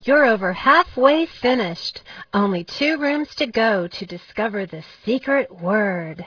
Sound: Listen to Klio (a Muse!) tell you the instructions (06").